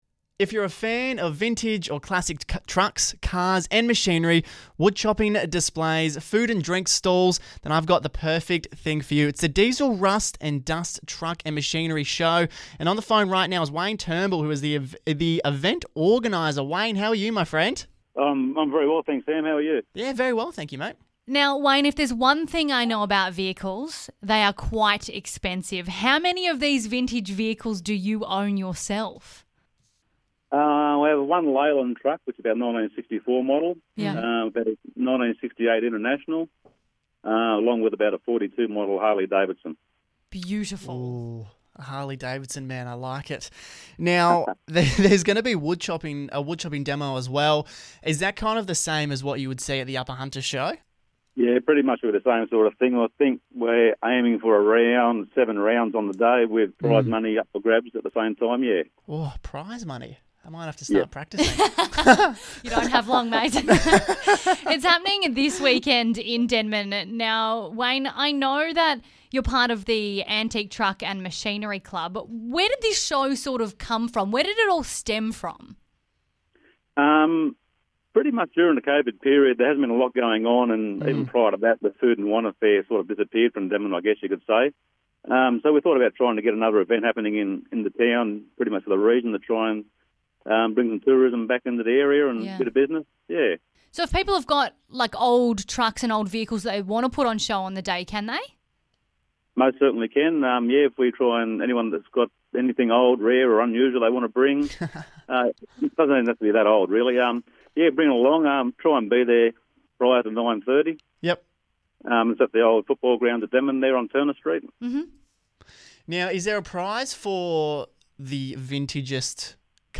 The Diesel Rust & Dust, Truck & Machinery Show - Chat